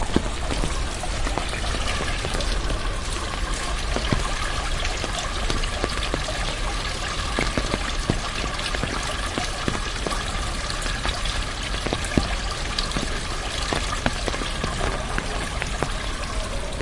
描述：在我的阳台上，我听到了一场大雨的漫长记录。有几辆车沿街经过，在水坑中溅起水花，Sennheiser ME66+ AKG CK94 in MS stereo / lluvia desde mi balcón, dos coches pasan chapoteando en los abundantes charcos< /p>
标签： 氛围 城市 现场记录 自然 streetnoise
声道立体声